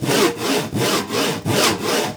TOOL_Saw_Wood_loop_mono.wav